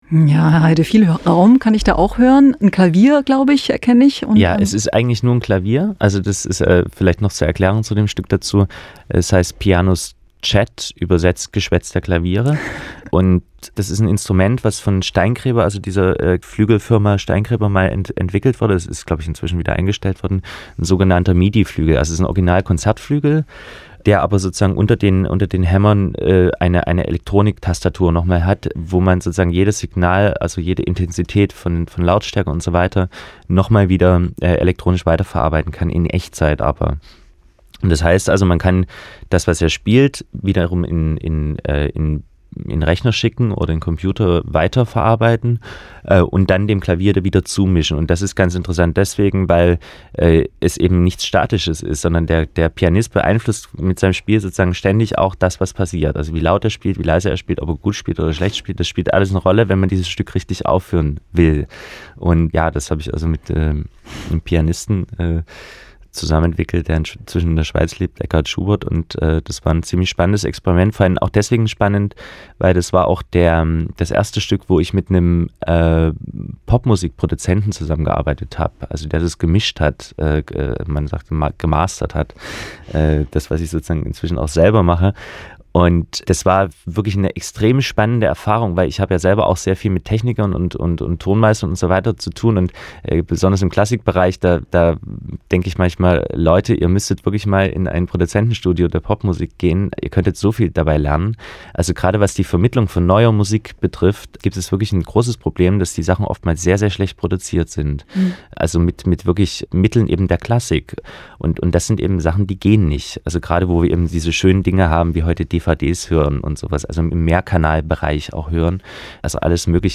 Interview mit dem MDR